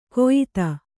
♪ koyita